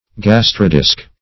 Search Result for " gastrodisc" : The Collaborative International Dictionary of English v.0.48: Gastrodisc \Gas`tro*disc\, n. [Gastro- + disc.]